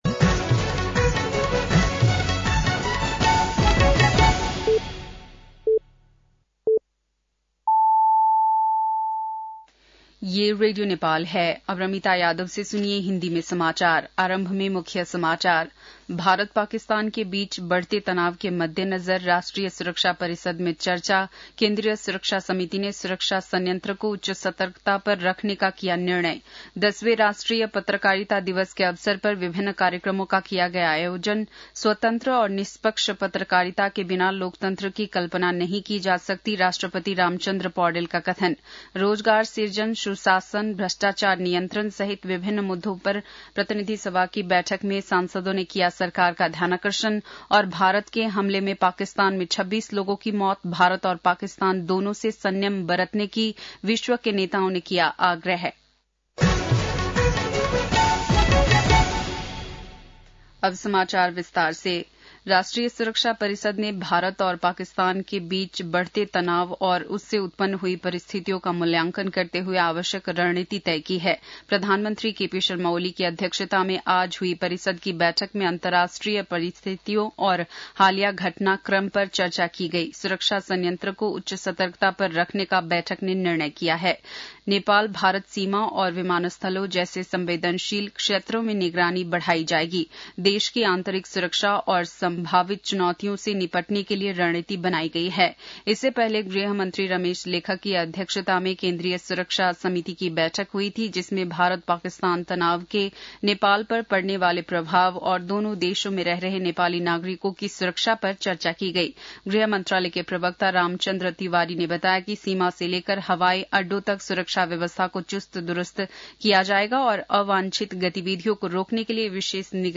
बेलुकी १० बजेको हिन्दी समाचार : २४ वैशाख , २०८२